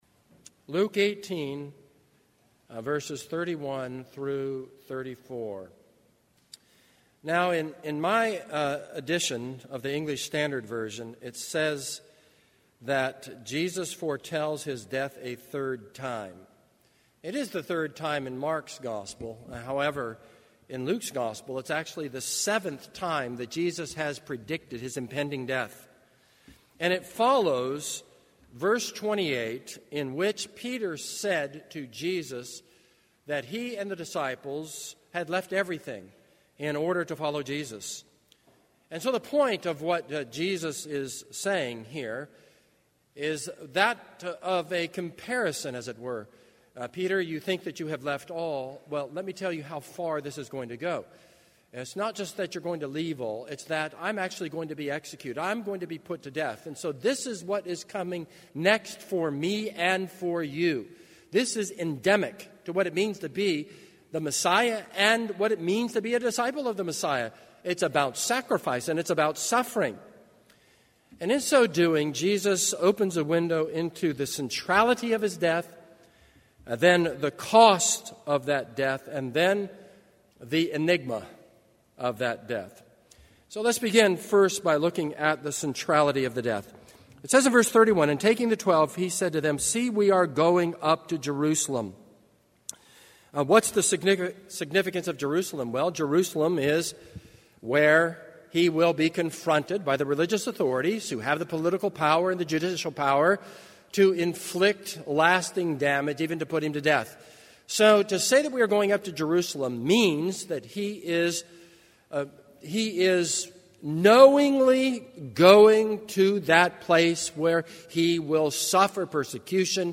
This is a sermon on Luke 18:31-43.